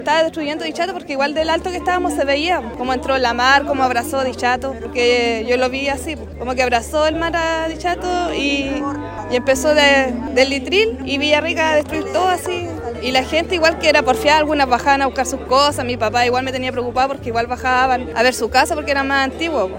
En Dichato, donde fallecieron 14 personas, se realizó una conmemoración para recordar a las víctimas del tsunami.
Entre los participantes, estaba una vecina testigo de la tragedia, quien recordó la forma en que vivió el terremoto y posterior tsunami.
cuna-vecina-dichato.mp3